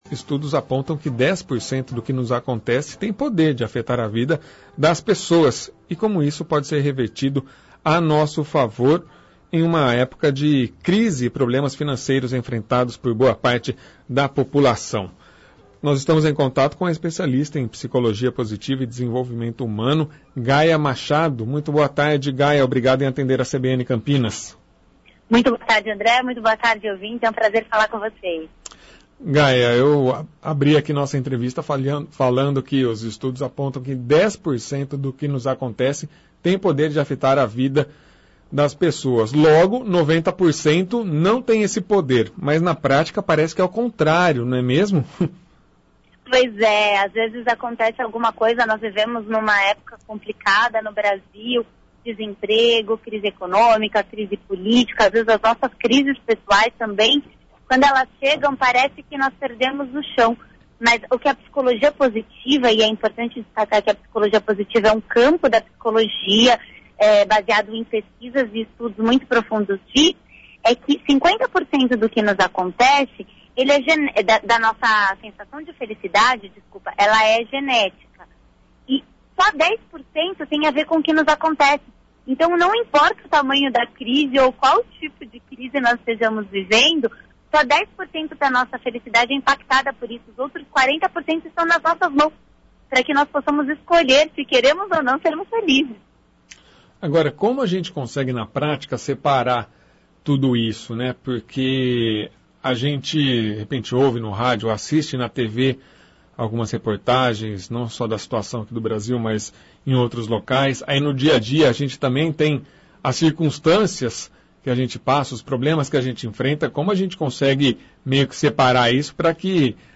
Especialista em psicologia positiva e desenvolvimento humano